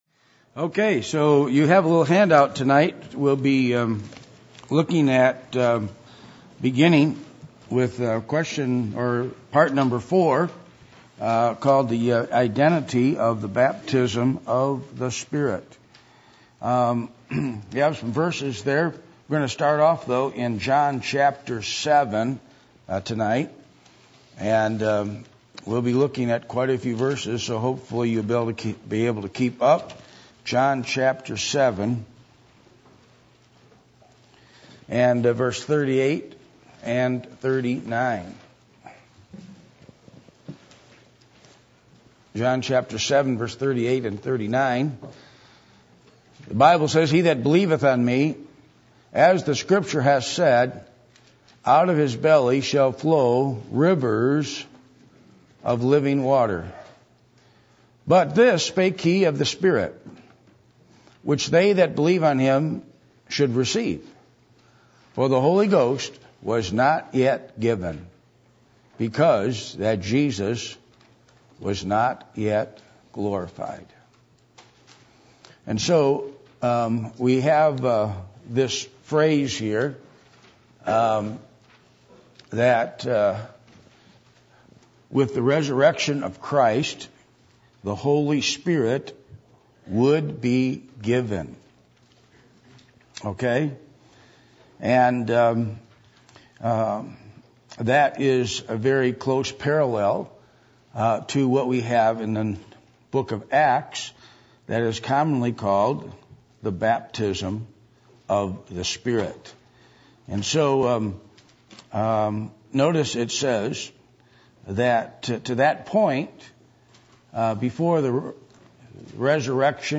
John 7:38-39 Service Type: Midweek Meeting %todo_render% « Basics Of Spiritual Growth Understanding Ladies